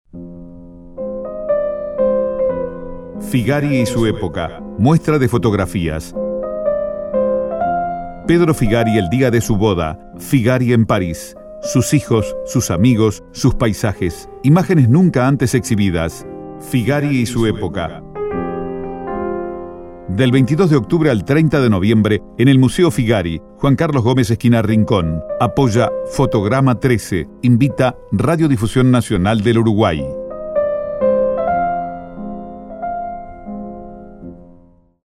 Figari y su época. Promoción radio